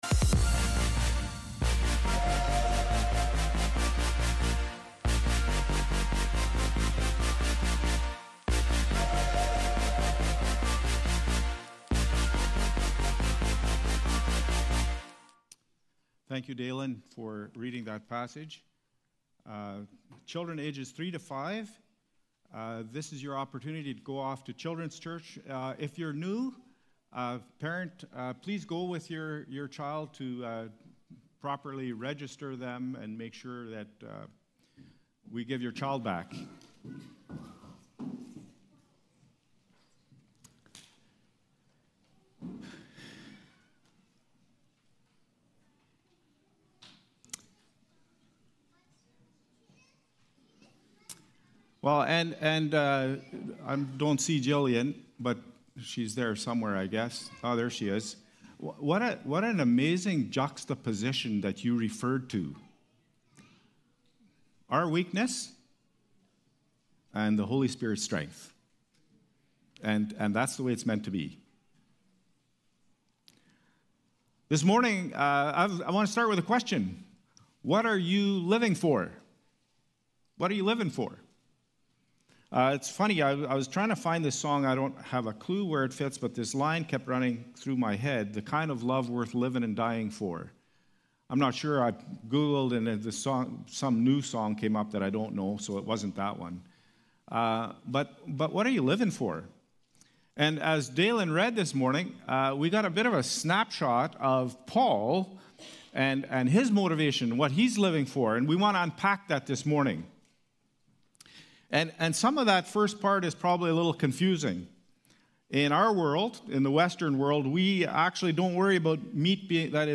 Past Message
Sept-7-Service.mp3